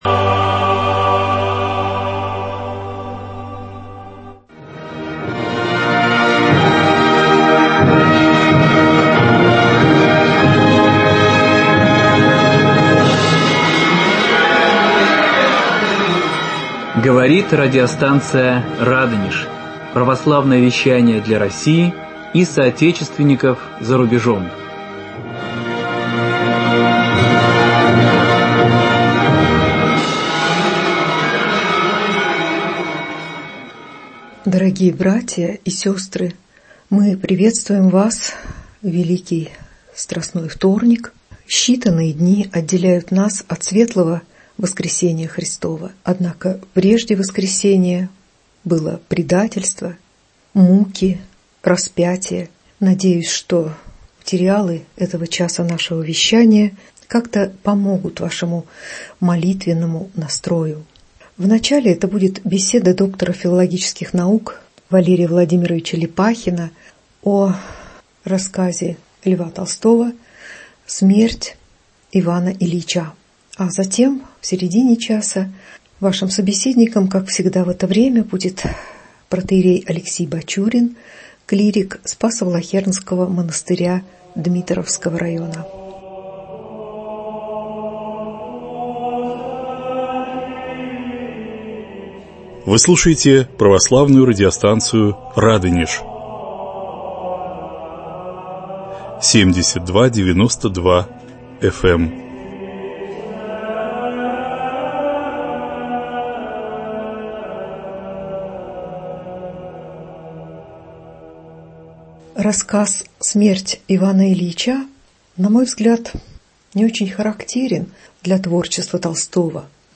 Беседа